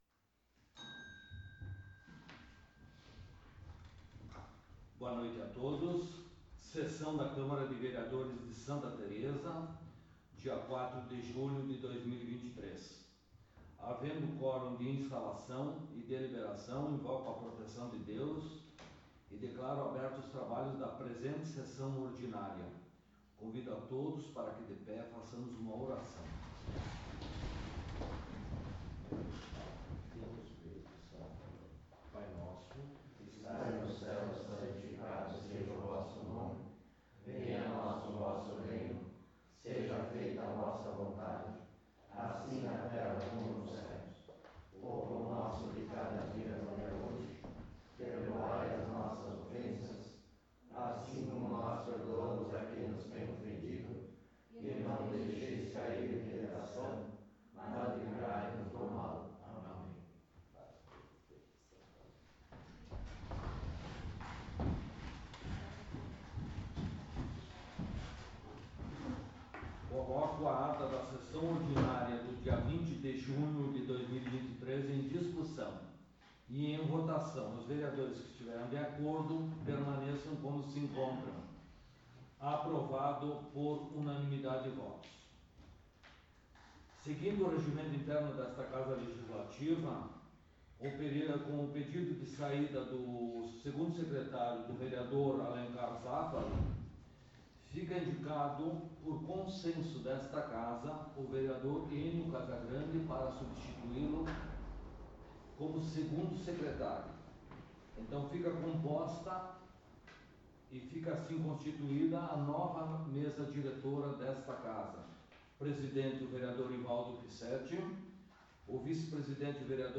11° Sessão Ordinária de 2023
Áudio da Sessão